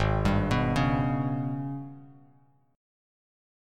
G#mM7bb5 chord